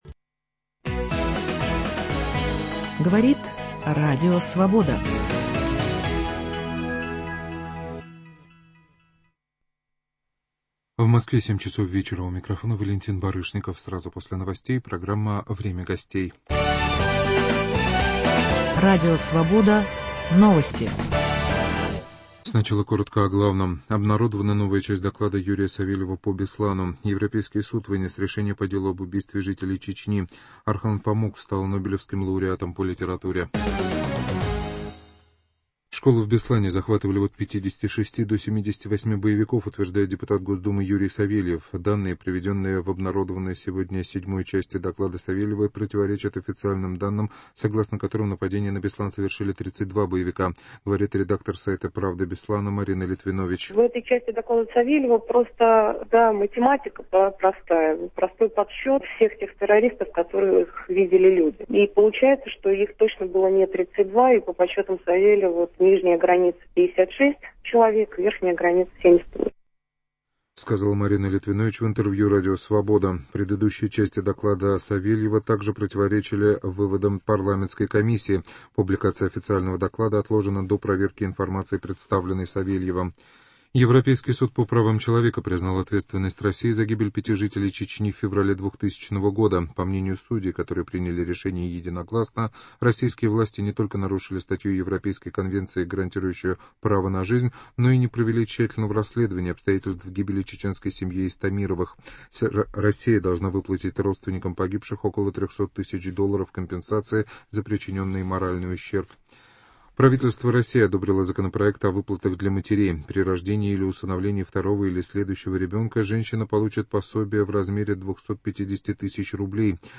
В программе участвуют депутаты Государственной Думы России: Владимир Рыжков - Республиканская партия и Борис Виноградов - партия "Родина".